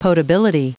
Prononciation du mot potability en anglais (fichier audio)
Prononciation du mot : potability